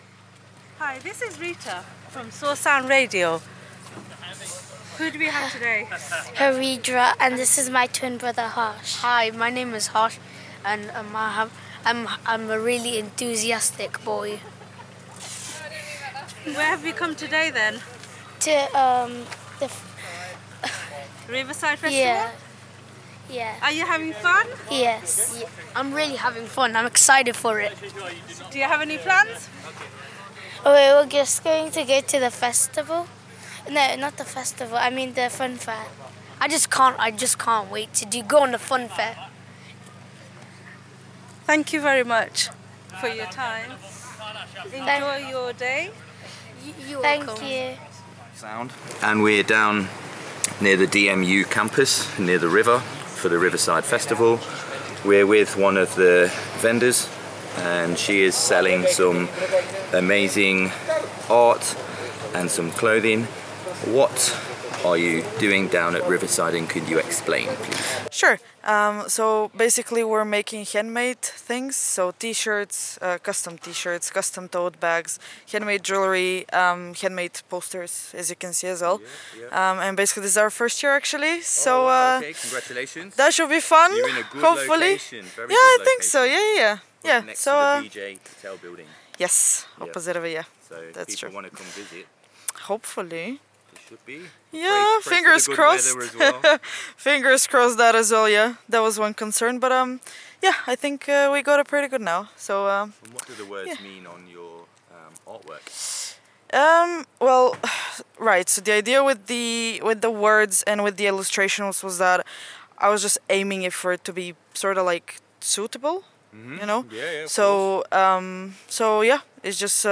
Leicester’s Riverside Festival returned this weekend with energy, colour and community spirit, and Soar Sound was there live on day one to capture the sounds, stories and celebrations from the heart of the event.
Broadcasting from our pop-up studio on the De Montfort University campus, Soar Sound’s team of volunteers and presenters shared a rolling programme of music, interviews, and festival commentary as thousands of people enjoyed the sunshine and riverside atmosphere.